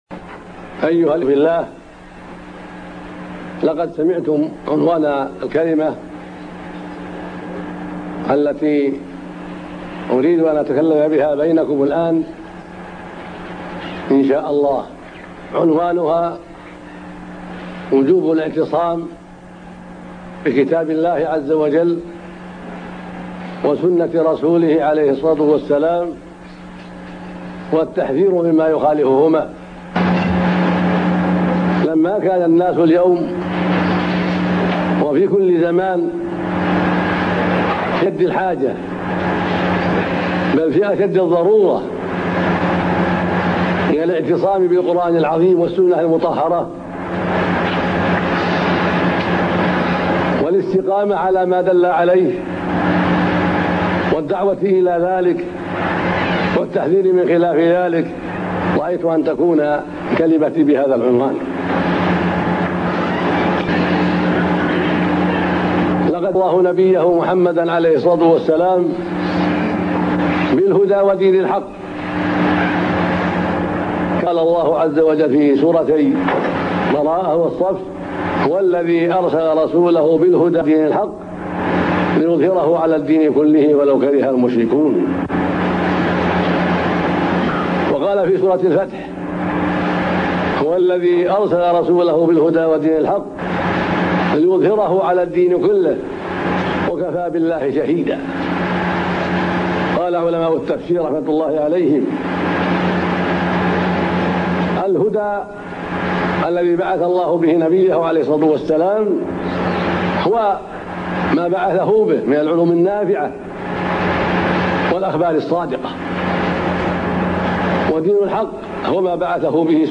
ar_BenBaz_MohadratAljame3_16.mp3